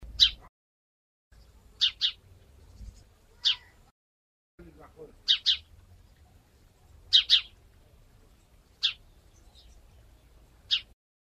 Звуки воробья
На этой странице собраны разнообразные звуки воробьёв: от одиночного чириканья до оживлённого щебетания стаи.